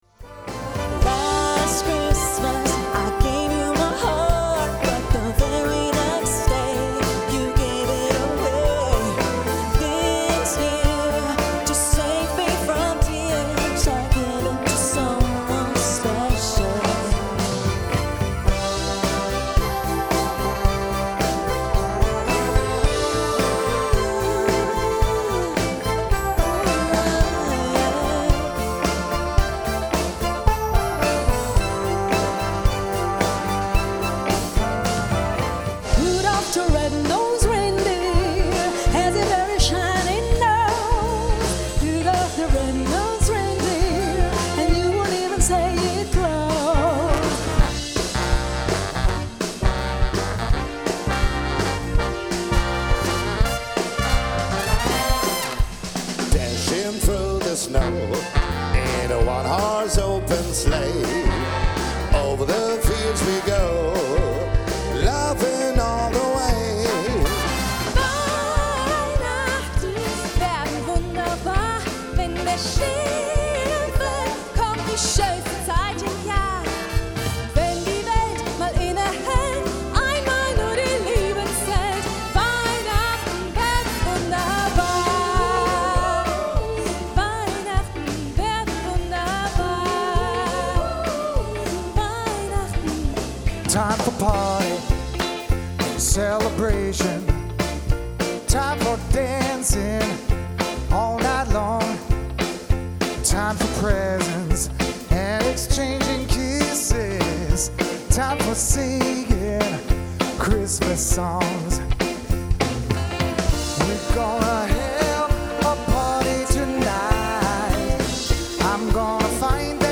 vocals
trumpet
saxophon
trombone
keyboard
guitar
drums
percussion
bass
violin
viola
cello